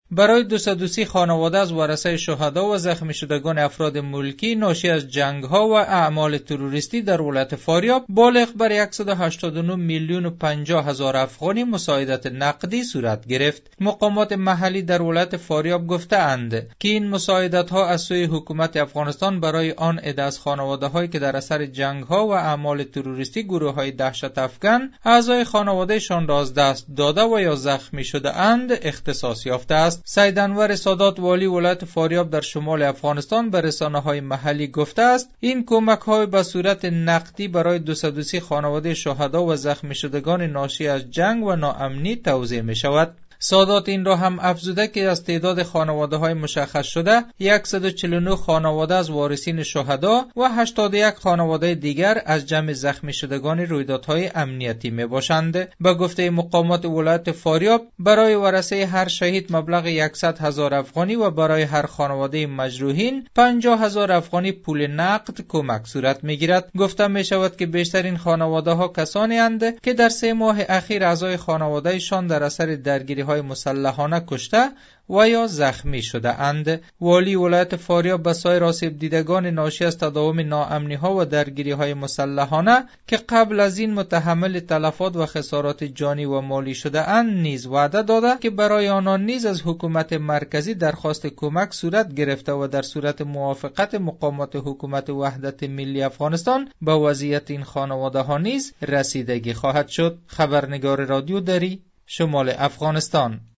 گزارش؛ مساعدت دولت افغانستان به بازماندگان غیرنظامی و شهدای جنگ در افغانستان